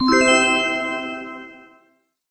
magic_harp_4.ogg